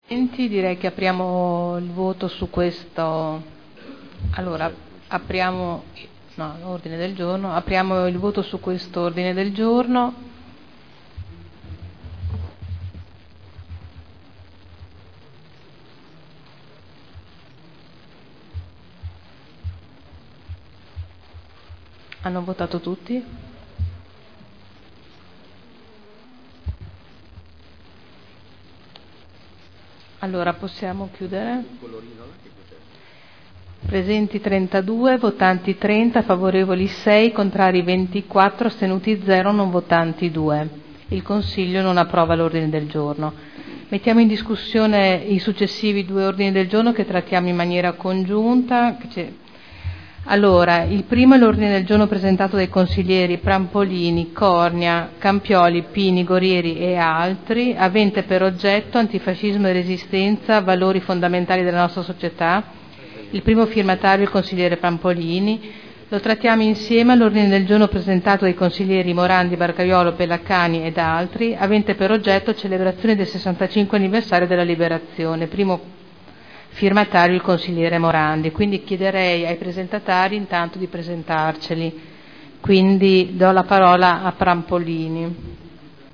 Seduta del 10/01/2011.